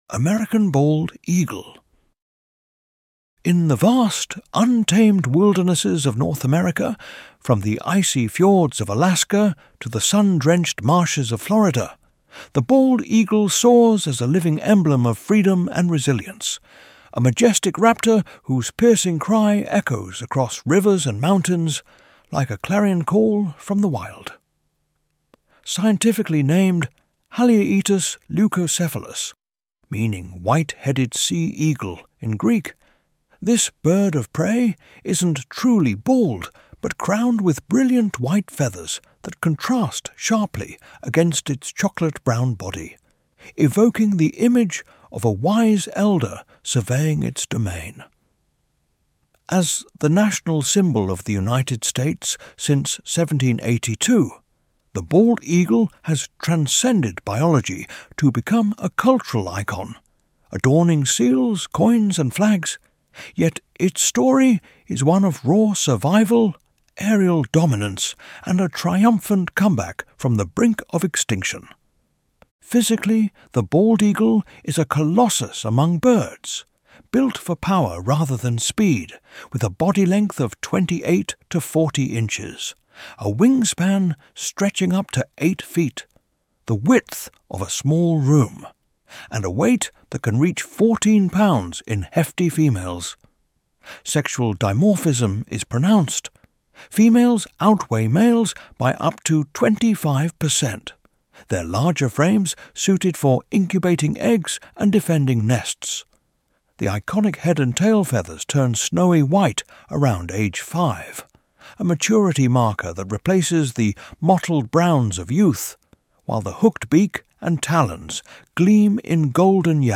ElevenLabs_American_Bald_Eagle.mp3